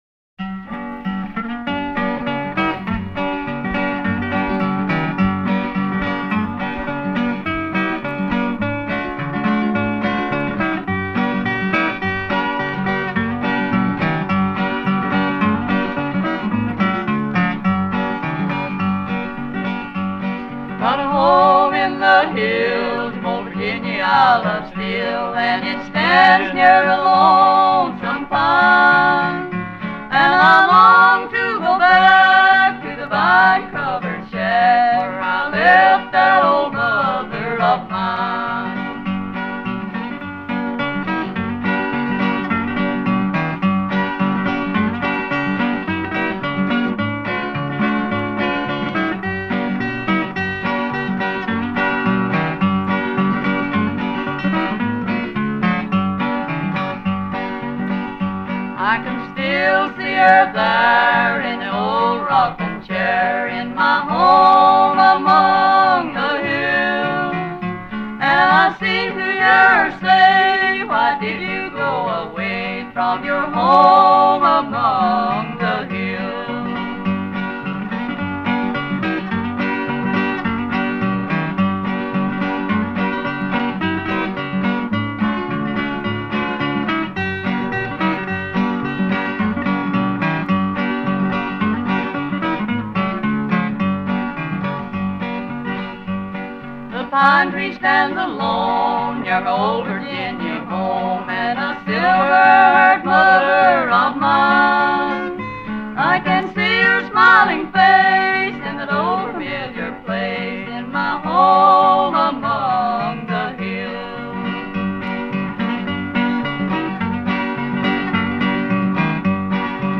Grupo de música folclórica tradicional estadounidense